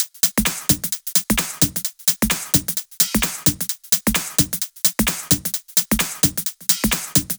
VFH2 130BPM Comboocha Kit 7.wav